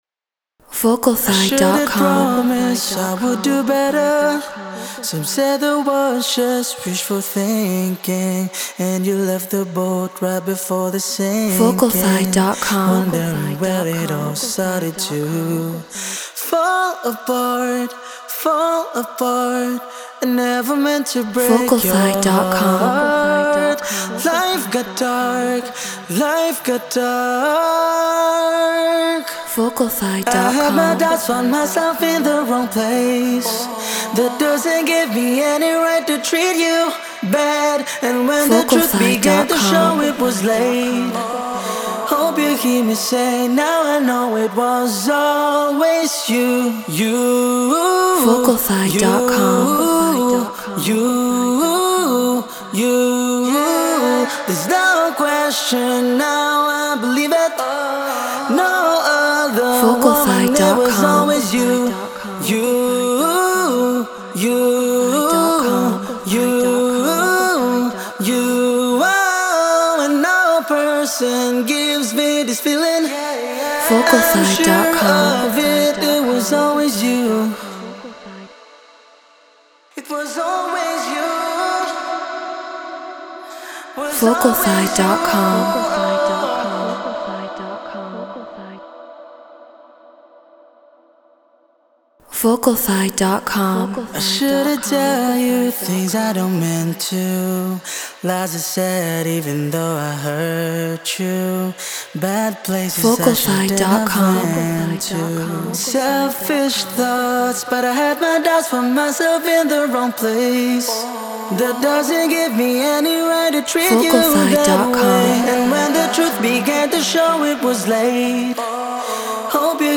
Non-Exclusive vocal.